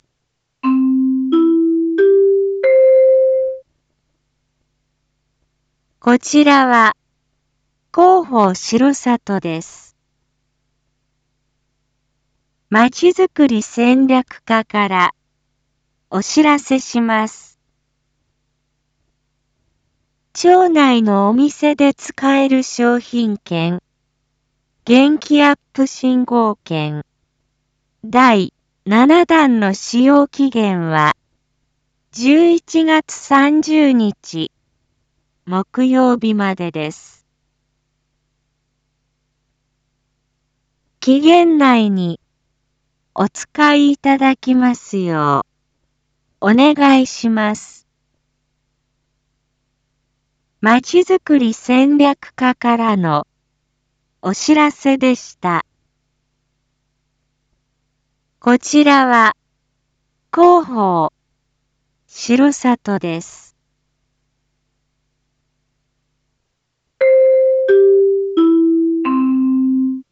一般放送情報
Back Home 一般放送情報 音声放送 再生 一般放送情報 登録日時：2023-11-25 19:01:11 タイトル：元気アップ振興券第７弾の使用期限について インフォメーション：こちらは、広報しろさとです。